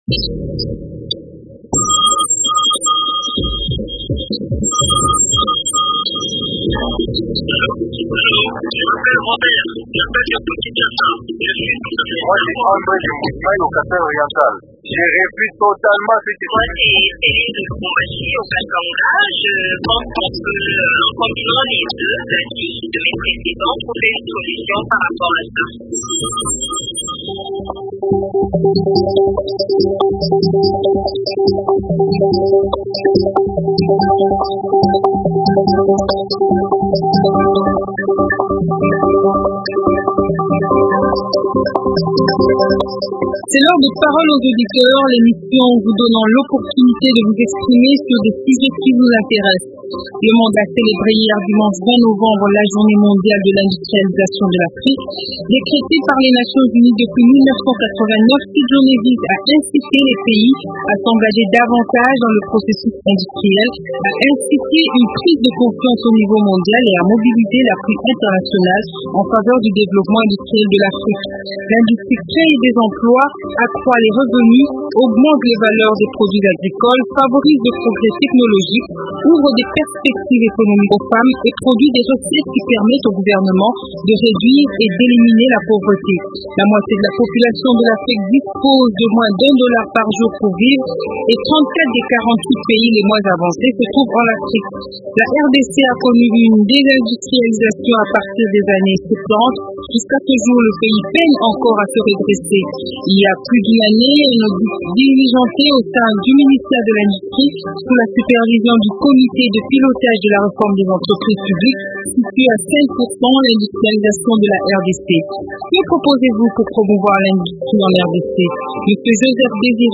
expert économique.